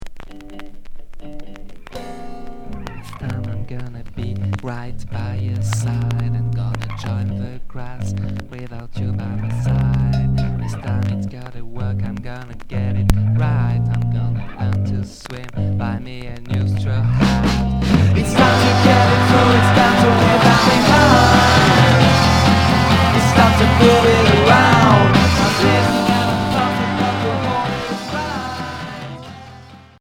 Noisy pop Unique 45t